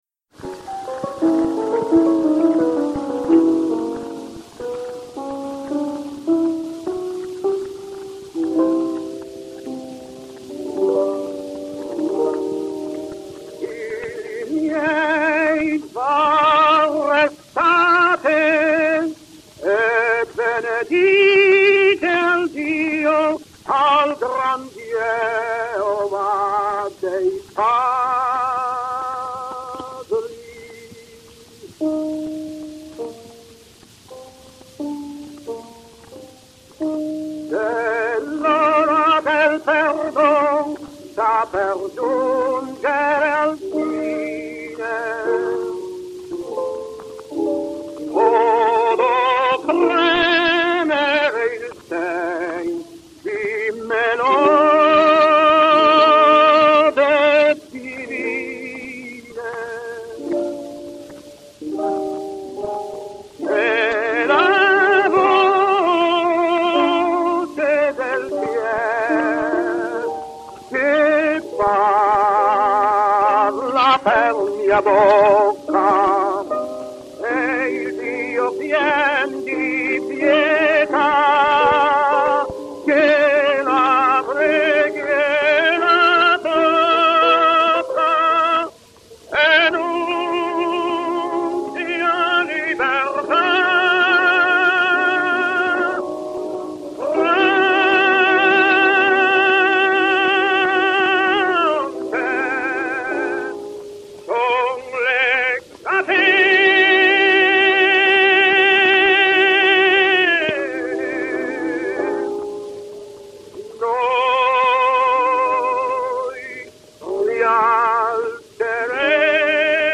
et Piano
enr. à Milan en 1903